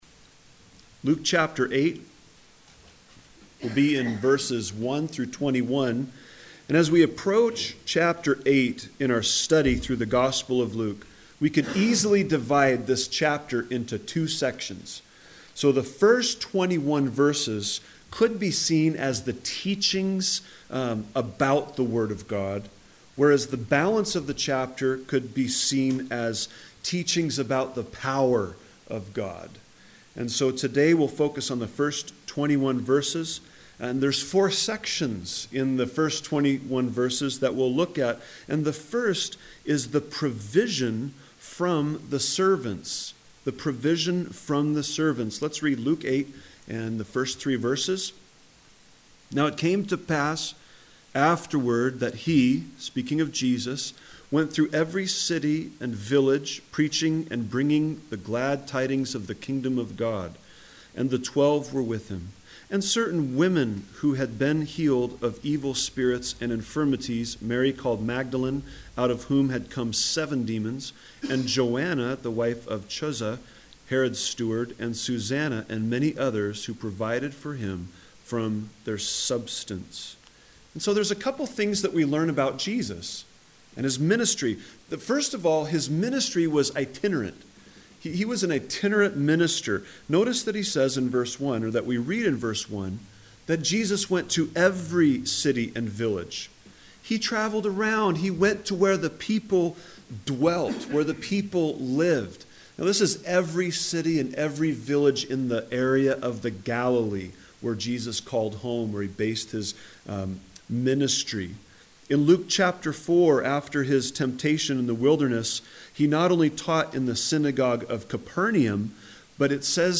A message from the series "Gospel of Luke."